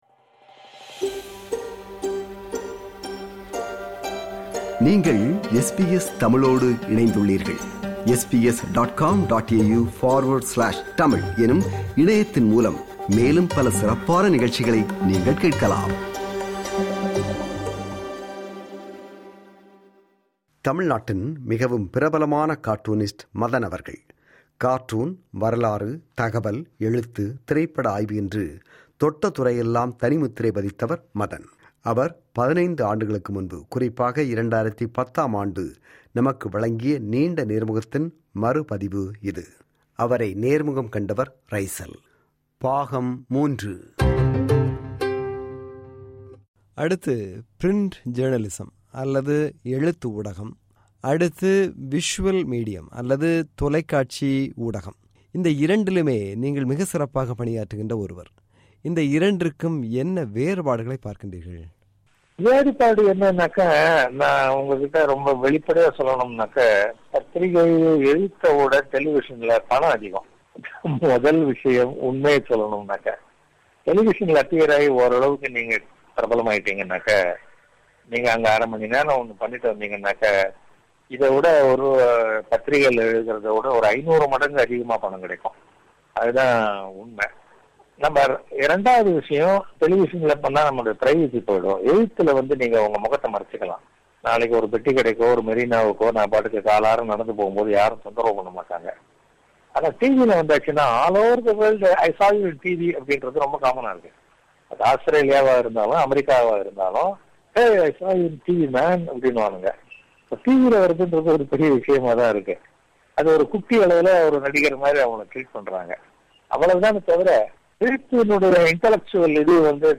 மதன் அவர்கள் 15 ஆண்டுகளுக்கும் முன்பு (2010 ஆம் ஆண்டு) நமக்கு வழங்கிய நீண்ட நேர்முகத்தின் மறு பதிவு இது.